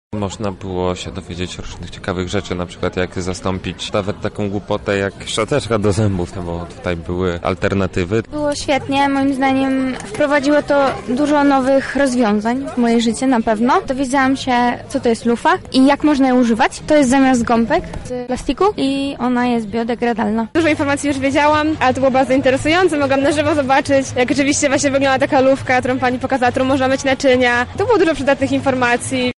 Uczestnicy powiedzieli nam, czego dowiedzieli się podczas spotkania: